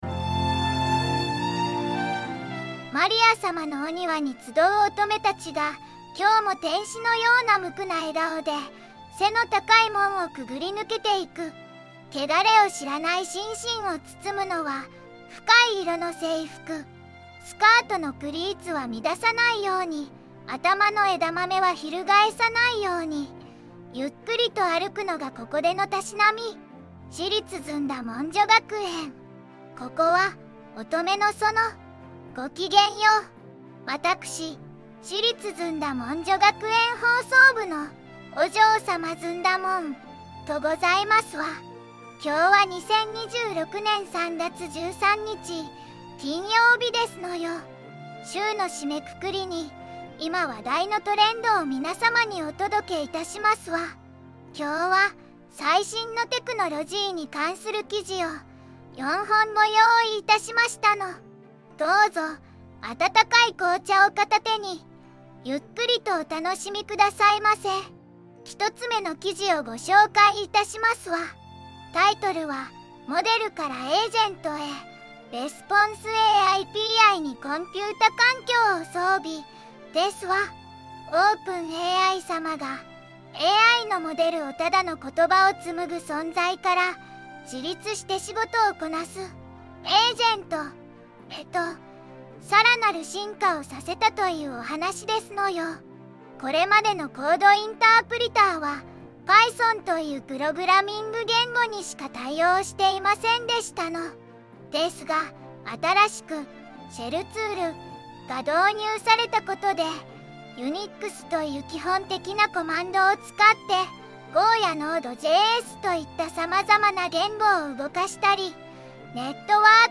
お嬢様ずんだもん
VOICEVOX:ずんだもん